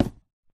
Sound / Minecraft / dig / wood3